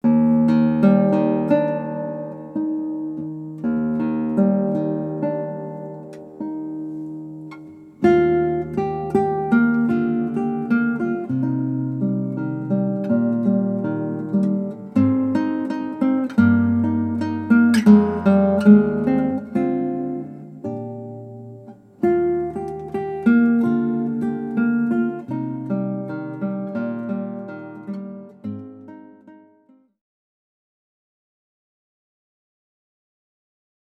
16 stukken voor gitaar.
• Gitaar solo